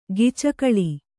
♪ gicakaḷi